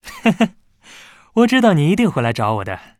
文件 文件历史 文件用途 全域文件用途 Bhan_tk_02.ogg （Ogg Vorbis声音文件，长度3.0秒，104 kbps，文件大小：38 KB） 源地址:游戏中的语音 文件历史 点击某个日期/时间查看对应时刻的文件。